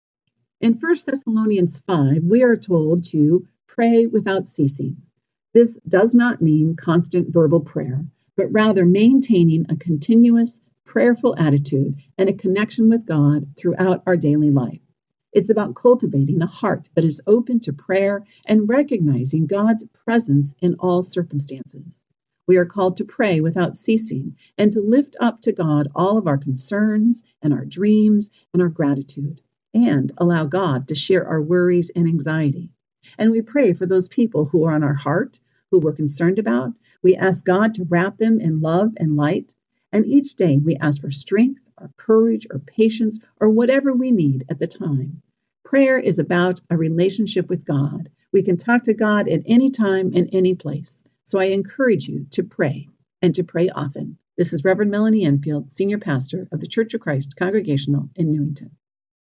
One Minute inspirational thoughts presented by various clergy!